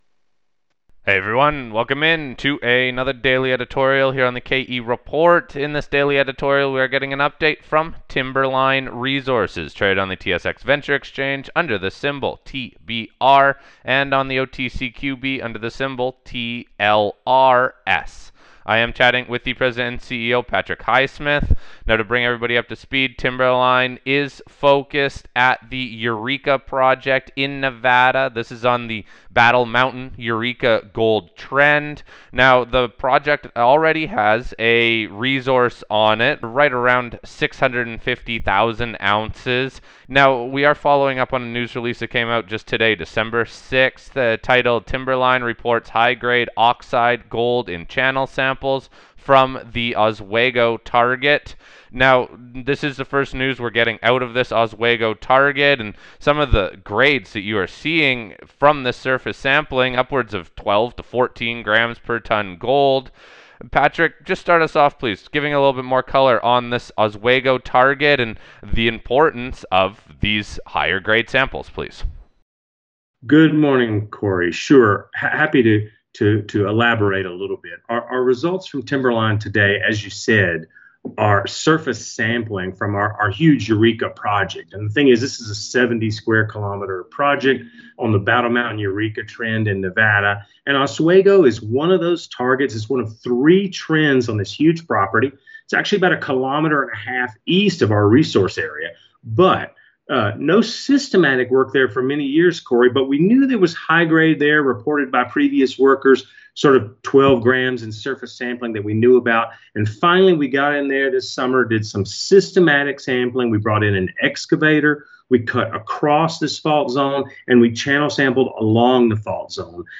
We discuss the importance of the oxide mineralization and the follow up drilling that has already been completed. We wrap up the interview with a broad overview of the exploration and drilling property wide at the Eureka Project.